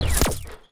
UIClick_Menu Double Hit Rumble Tail 01.wav